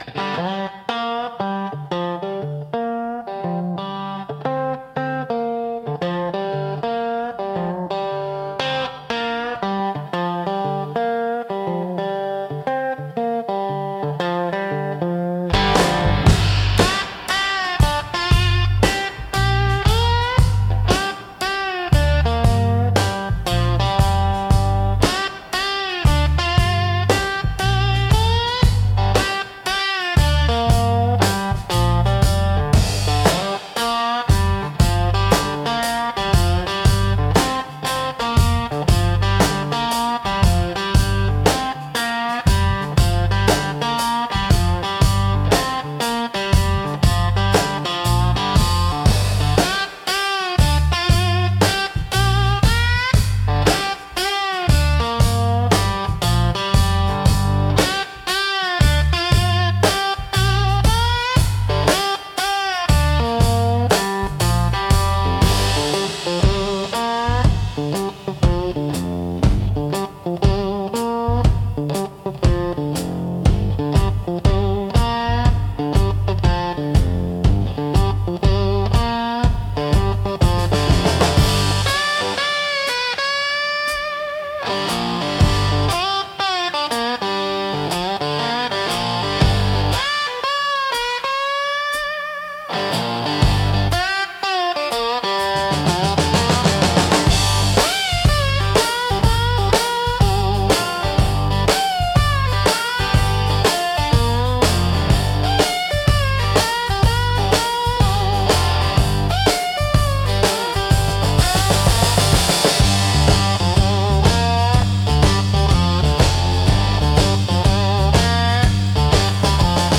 Instrumental -Rattlesnake Shuffle 4.26